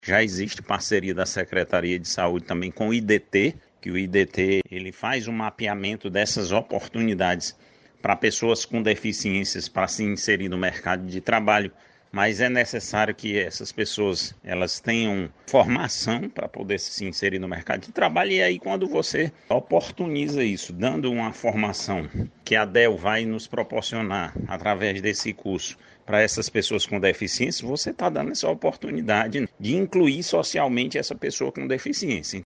O secretário Marcos Gadelha destaca a parceria da Sesa com o Instituto de Desenvolvimento do Trabalho (IDT) para garantir a inserção dessas pessoas no mercado de trabalho.